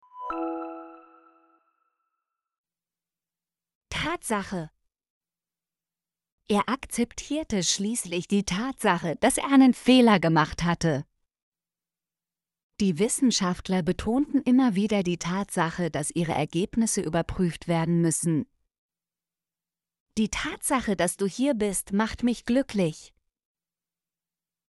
tatsache - Example Sentences & Pronunciation, German Frequency List